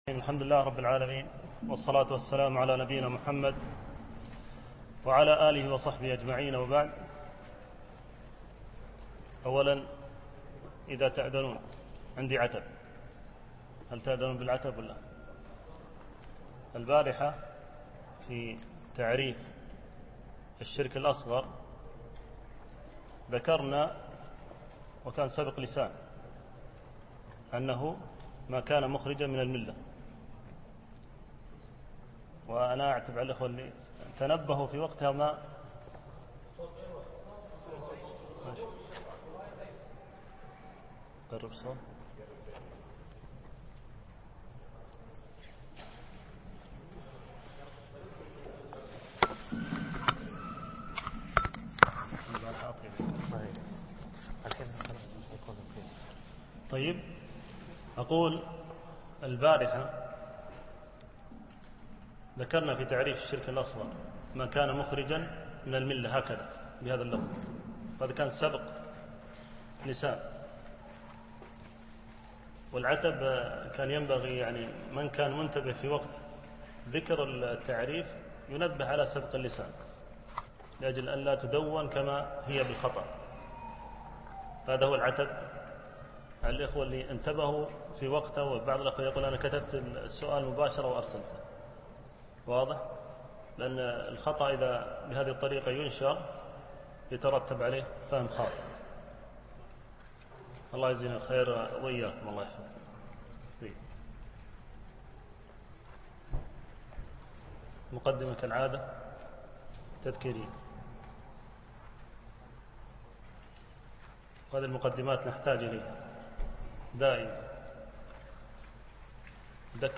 الدرس الثاني - الجزء الأول